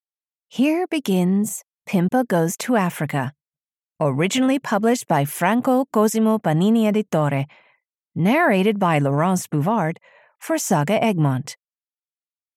Pimpa Goes to Africa (EN) audiokniha
Ukázka z knihy